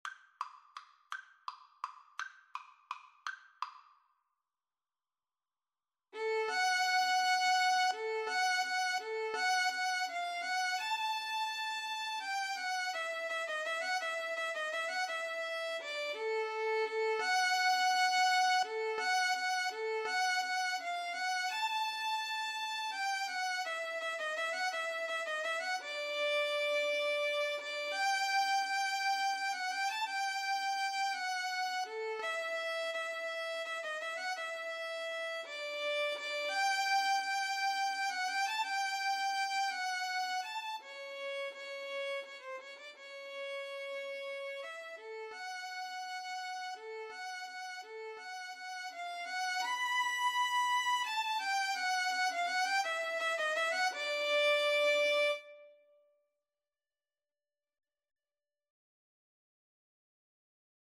3/4 (View more 3/4 Music)
Tempo di valse =168
Classical (View more Classical Violin Duet Music)